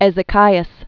(ĕzĭ-kīəs)